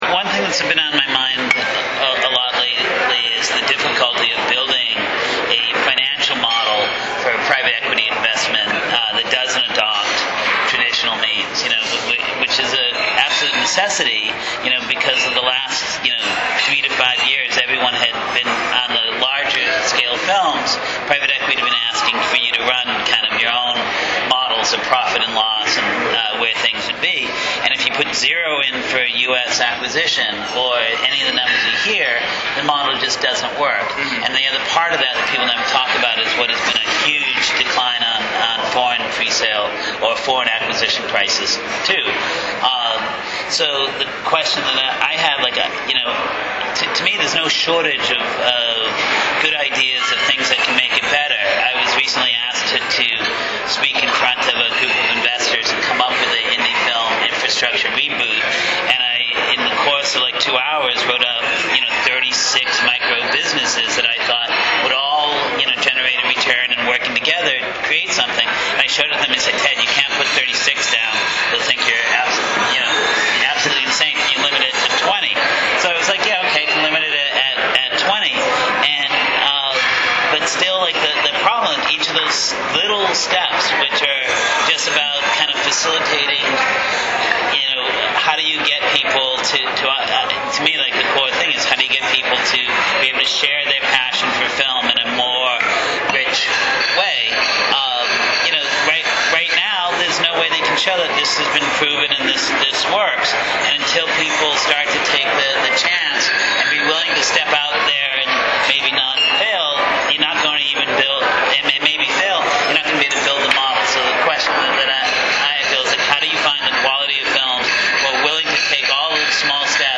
Eight folks who were in Austin this week for the SXSW Film Festival sat down yesterday morning to have breakfast and talk about the one big idea or big challenge or big shift that we've been thinking about most these days. We recorded the conversation so you could listen in, but be forewarned that there's a lot of background noise; the restaurant was noisier than is ideal for audio recording. (It gets better as the recording goes on, as the restaurant empties out.)